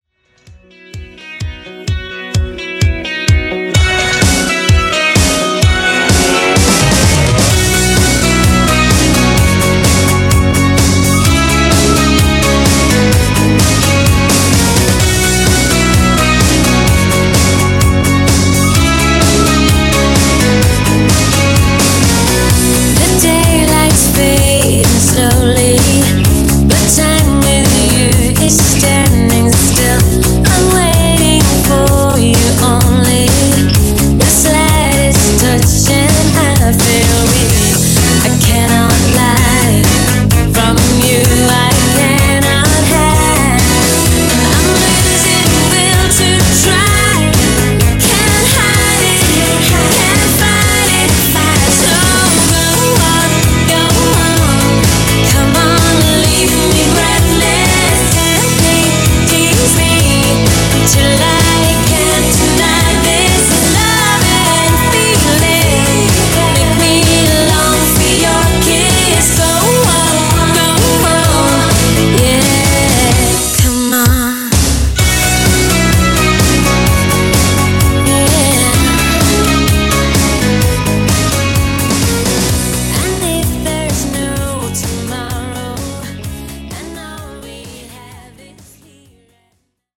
Genre: BASS HOUSE
Clean BPM: 128 Time